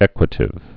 (ĕkwə-tĭv)